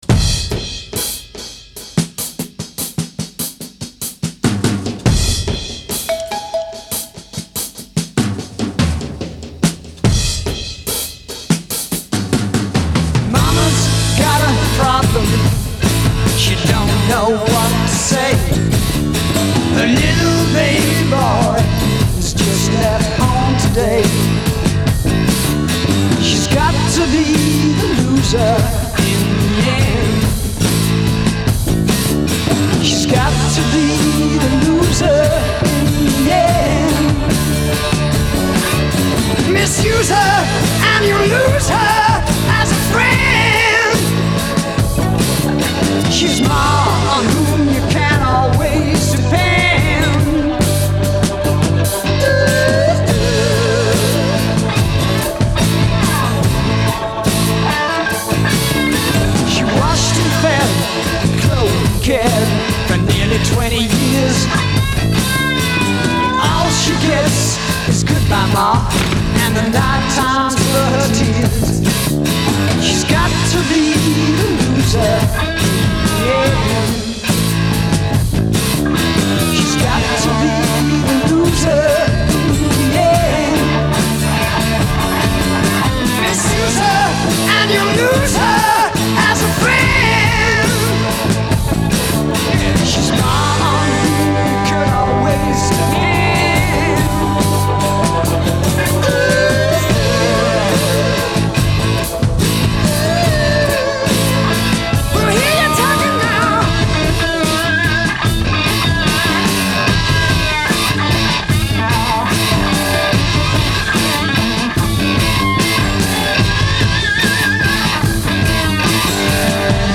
прогрессив-рока